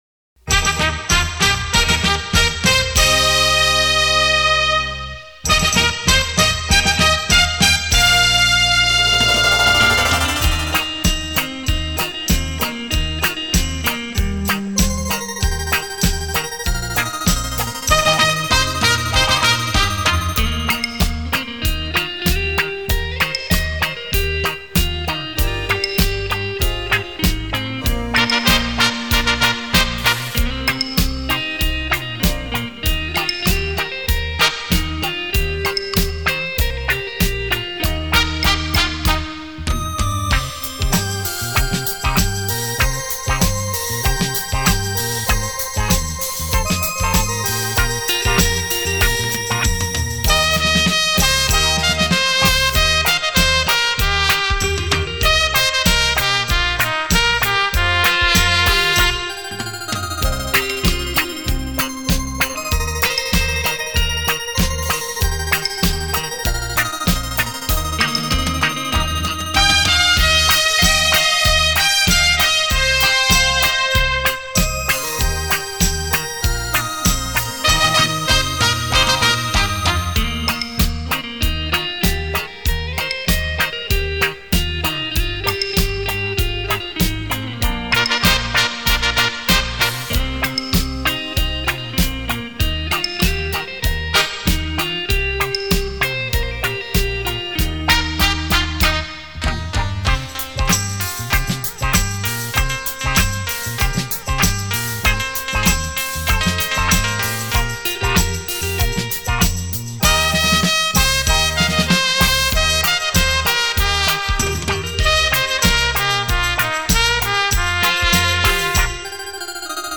旋律动听的闽南语金曲
让你的心随着悠扬的琴声自由的放飞
浪漫悠扬的电子琴音乐